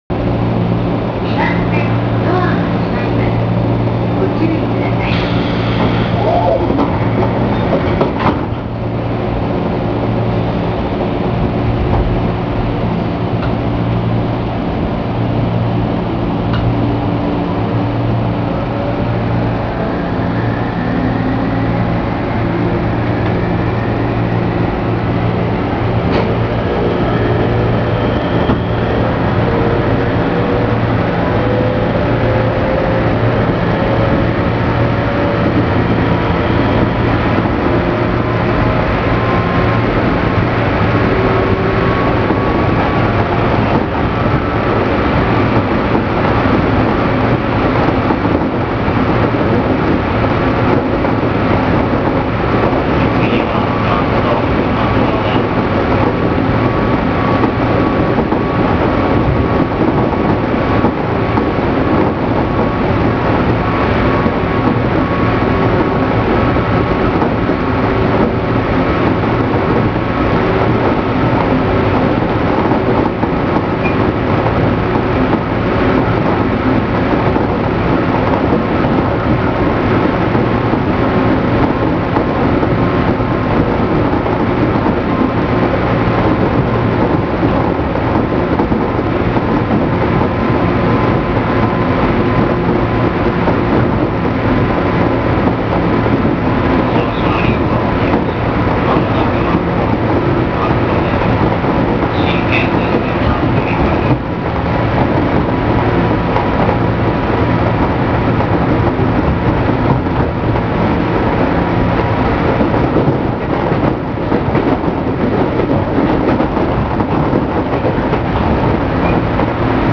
・203系走行音
【常磐線】北松戸〜松戸（2分27秒：798KB）
車体が時々ミシミシ音を立てているのはやはり劣化が進んでいる証拠なのでしょうか。基本的に、音は201系と全く同じですが、走行中はアルミ車体故か、軽いドアが揺れに揺れてしまっています。